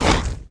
Index of /App/sound/monster/skeleton_wizard